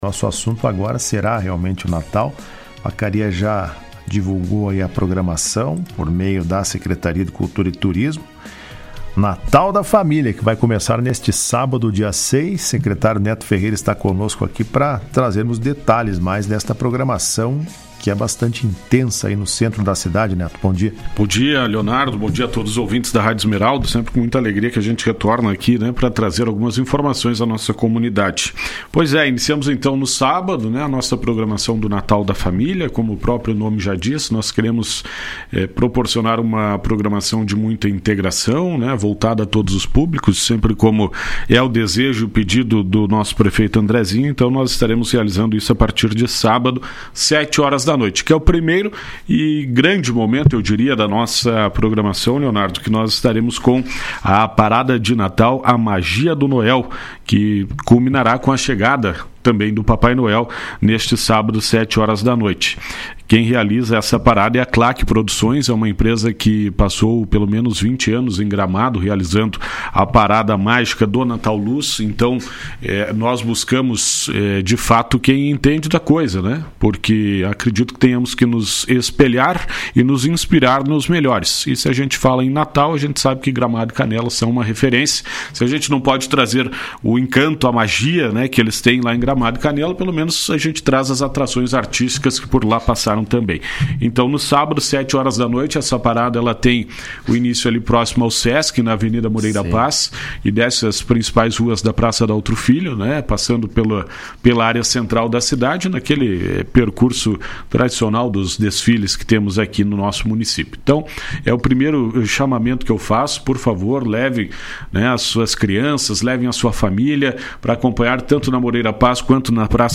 Em entrevista à Rádio Esmeralda nesta quarta-feira, o Secretário de Cultura e Turismo de Vacaria, Neto Ferreira disse que a programação foi pensada para que toda a comunidade possa festejar o natal e reforçou o convite para a abertura do evento, que acontece neste sábado.